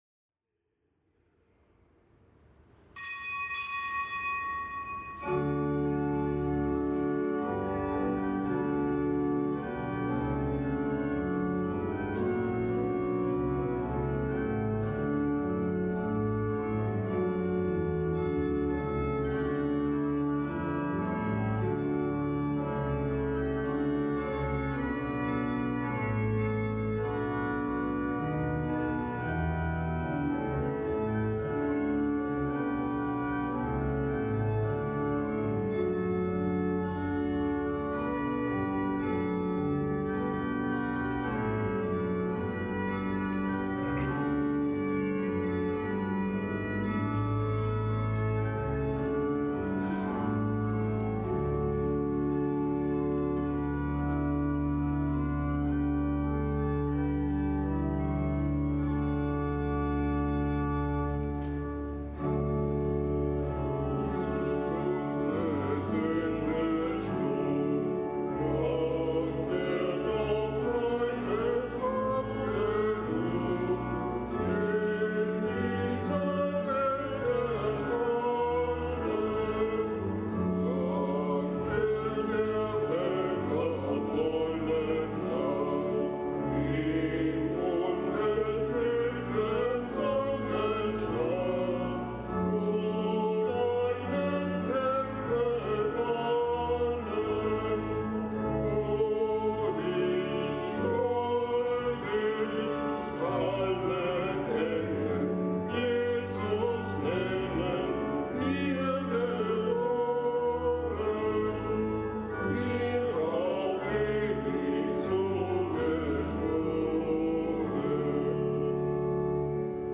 Kapitelsmesse am Donnerstag der fünften Fastenwoche
Kapitelsmesse aus dem Kölner Dom am Donnerstag der fünften Fastenwoche.